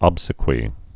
(ŏbsĭ-kwē)